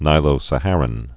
(nīlō-sə-hărən, -härən)